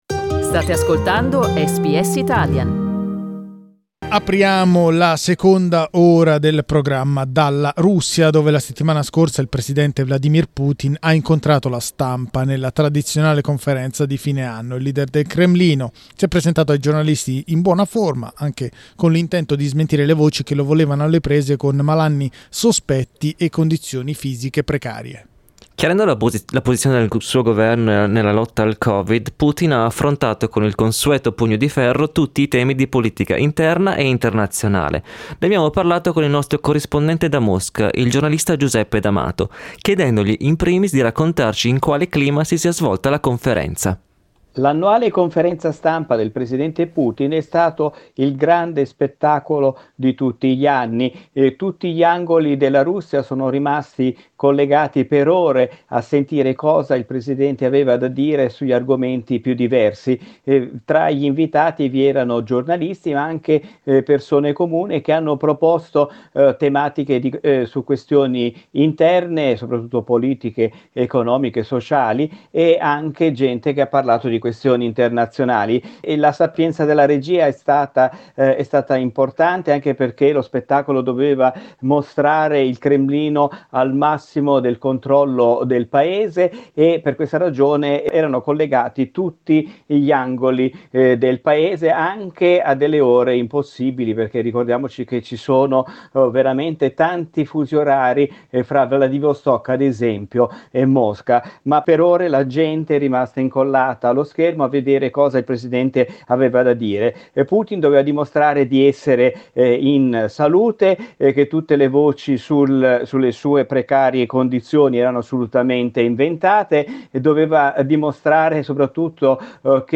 Riascolta qui l'intervento del nostro corrispondente da Mosca